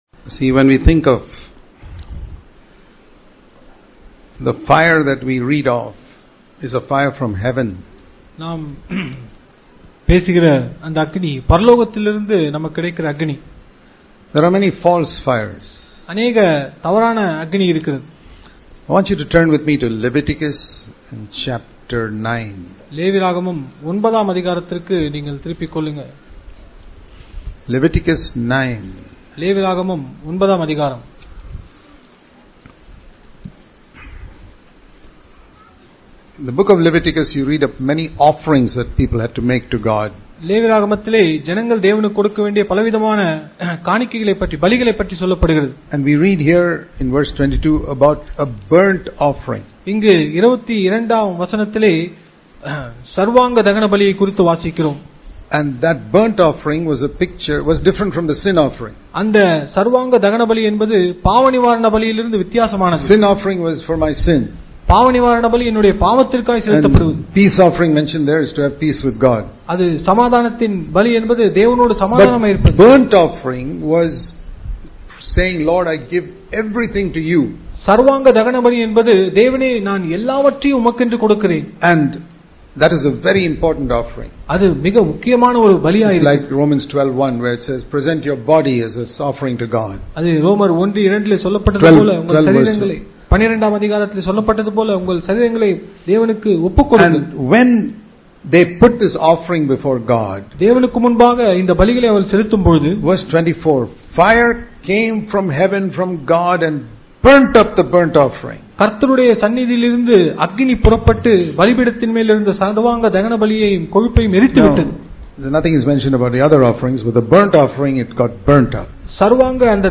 The Genuine Baptism of Fire How To Remain Hot And Not Become Lukewarm Or Cold - Chennai Conference 2017